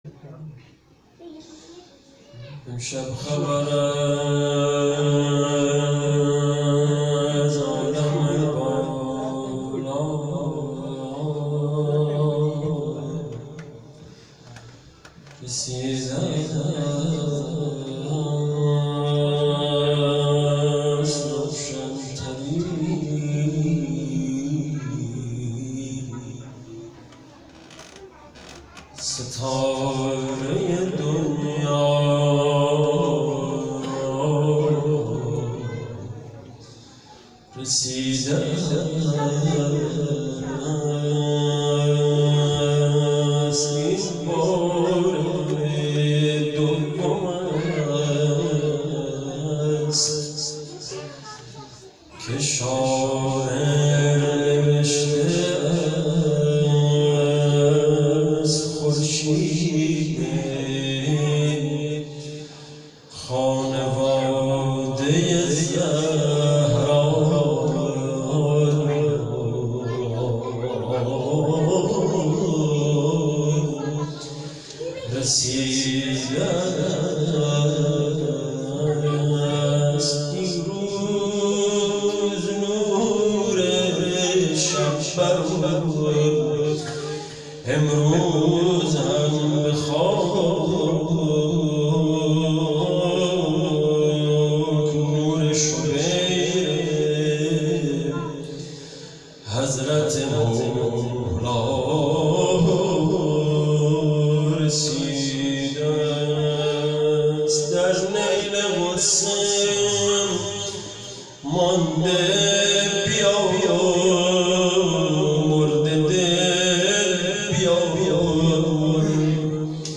خیمه گاه - هیئت محبان المهدی(عج)آمل - شب میلاد سرداران کربلا_بخش چهارم مدح